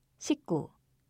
발음 [-꾸]